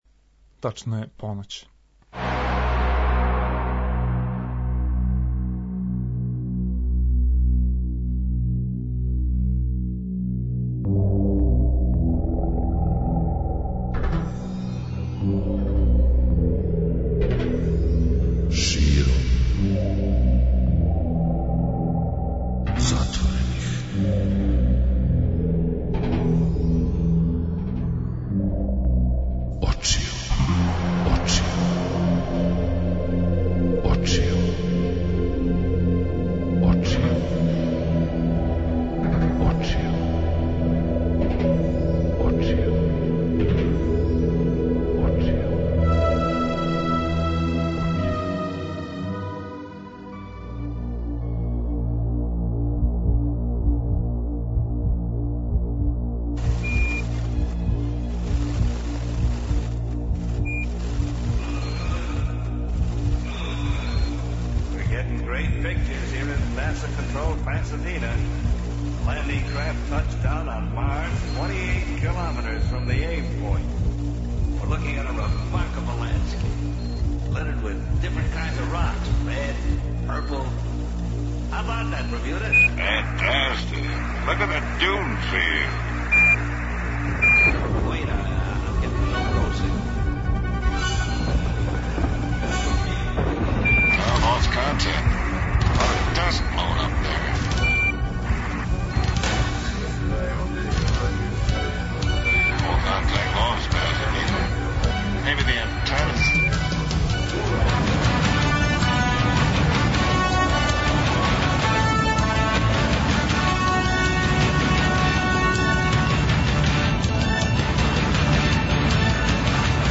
Такође, преслушаћемо најзанимљивију СФ/Х/Ф филмску музику из прошле године.